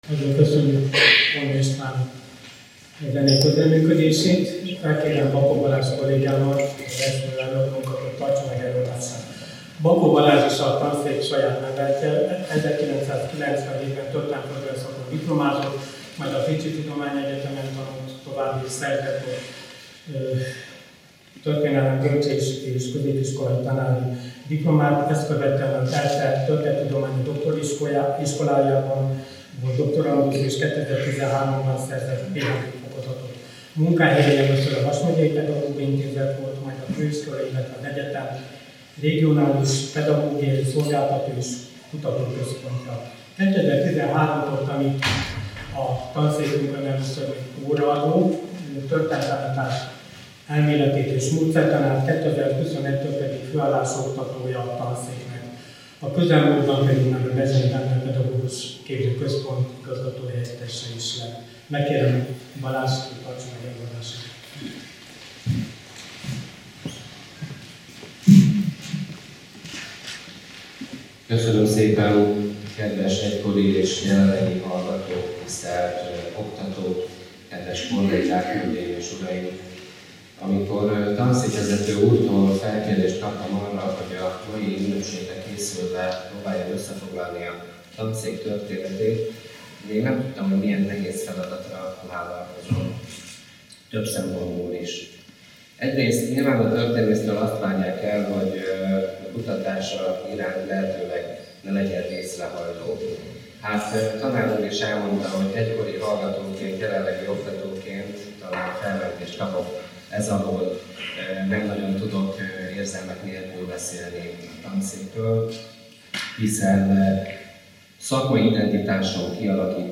Rendezvény a szombathelyi történelem szakos tanárképzés indulásának és a Történelem Tanszék alapításának 50 éves jubileuma alkalmából.
Előadások, konferenciák